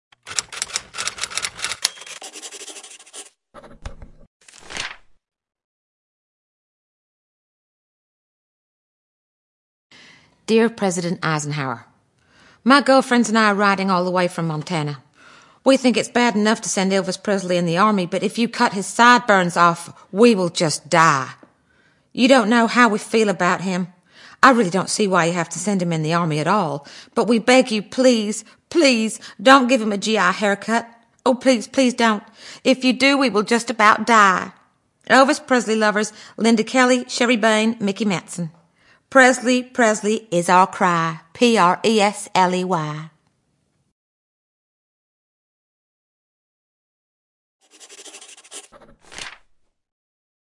见信如晤Letters Live 第21期:'奥莉薇娅柯尔曼'读信:别碰他的头发 听力文件下载—在线英语听力室
在线英语听力室见信如晤Letters Live 第21期:'奥莉薇娅柯尔曼'读信:别碰他的头发的听力文件下载,《见信如唔 Letters Live》是英国一档书信朗读节目，旨在向向书信艺术致敬，邀请音乐、影视、文艺界的名人，如卷福、抖森等，现场朗读近一个世纪以来令人难忘的书信。